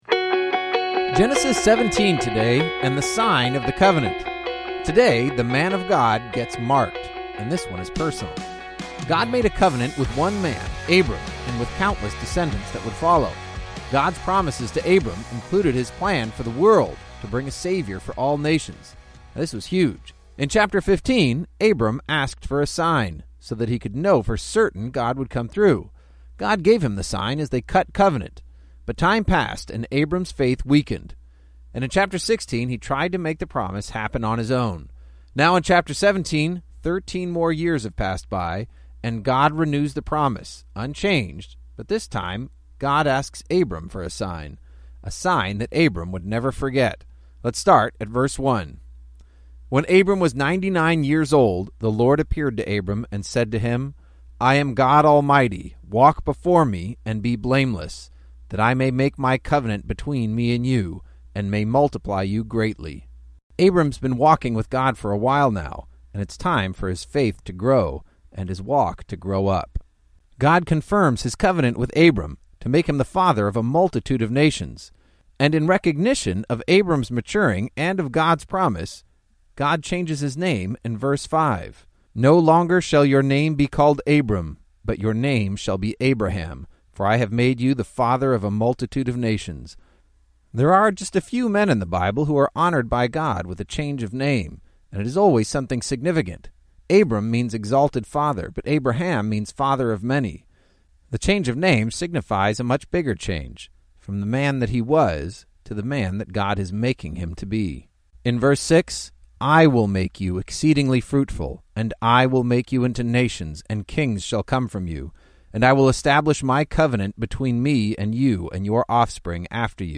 19 Journeys is a daily audio guide to the entire Bible, one chapter at a time. Each journey takes you on an epic adventure through several Bible books, as your favorite pastors clearly explain each chapter in under ten minutes. Journey #3 is Foundations, where Genesis takes us back to our origins, Daniel delivers phenomenal prophecies, and Romans lays out the heart of the gospel.